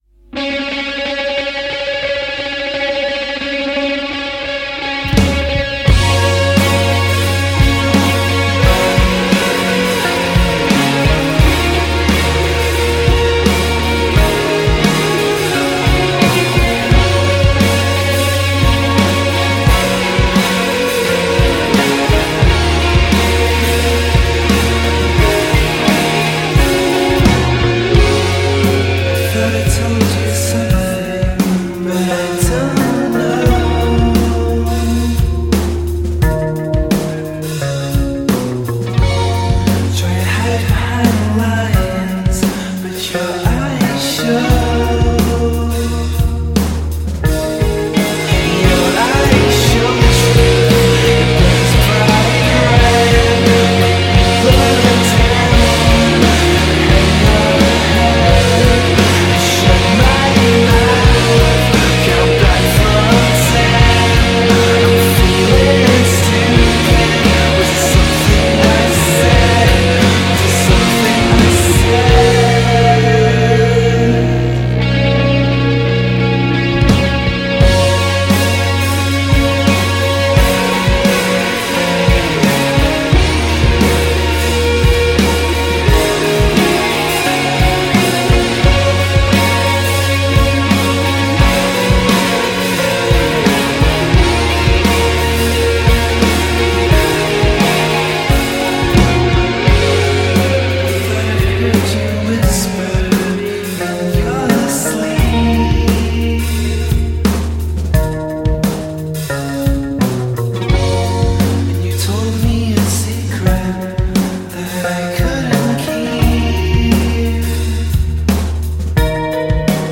shoegazers
reverb-laden new single